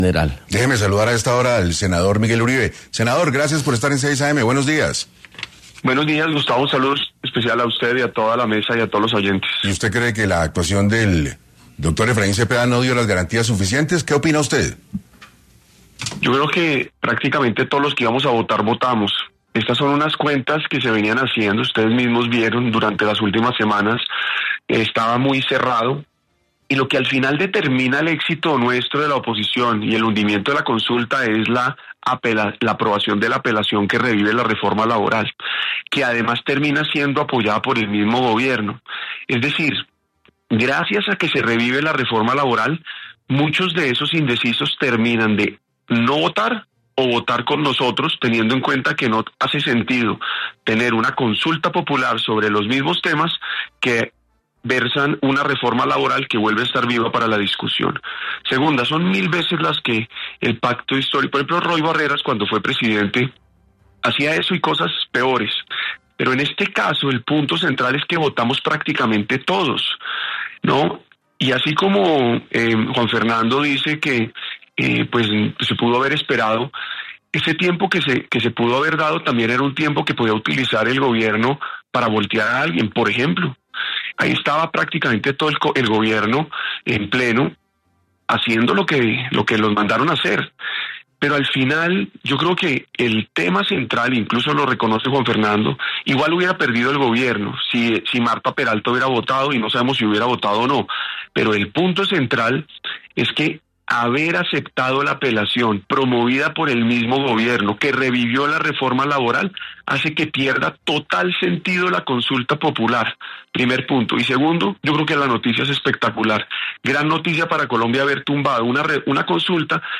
El congresista estuvo en 6AM a propósito del hundimiento de la consulta popular ayer. También, dio su versión del presunto fraude dentro de la votación.
Una de ellas fue la del senador del Partido Centro Democrático, Miguel Uribe, quien dijo en entrevista con la mesa de trabajo 6AM que se ayudó al país a no gastar más de 700 millones de pesos que costaría la consulta popular.